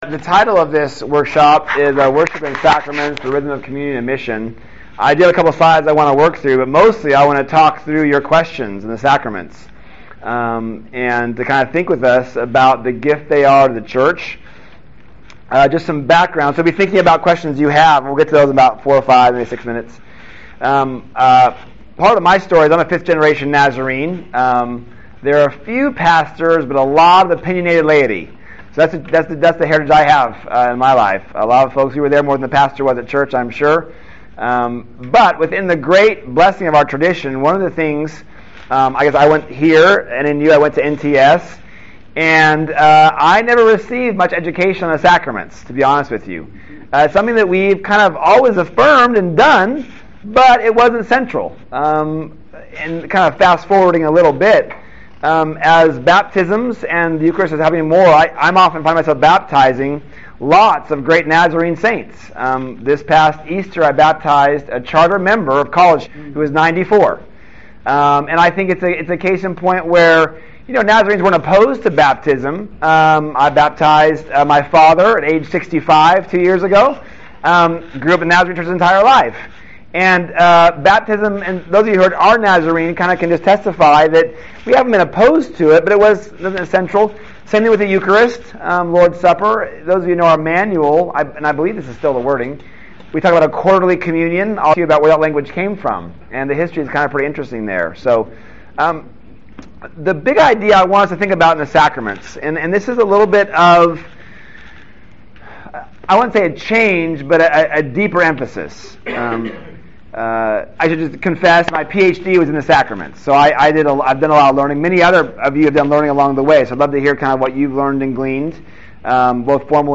This workshop will explore how communal worship and the sacraments form and shape the church as the Body of Christ in communion sent out for mission. This workshop will also explore the theology and practice of the sacraments, providing liturgical and practical resources for local churches.